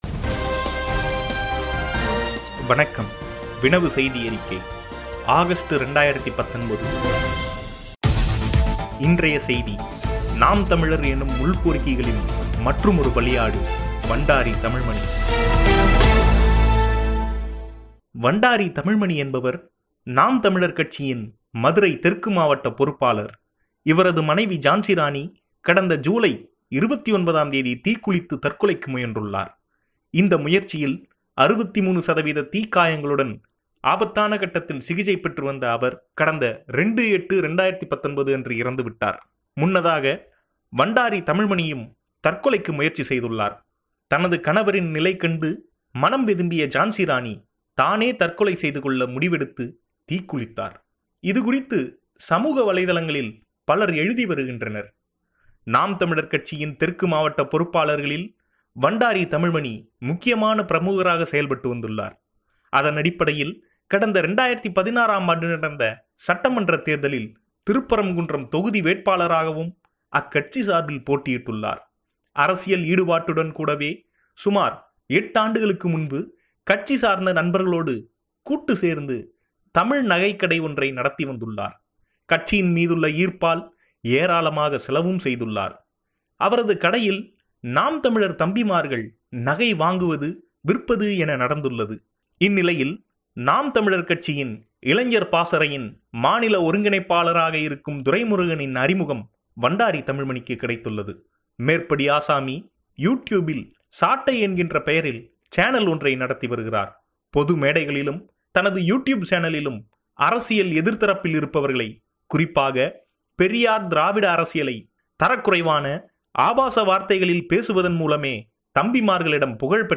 ஒலி வடிவில் செய்தி அறிக்கைகள் – ஆகஸ்டு 2019 முதல் பாகம் | டவுண்லோடு